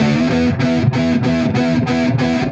Index of /musicradar/80s-heat-samples/95bpm
AM_HeroGuitar_95-E01.wav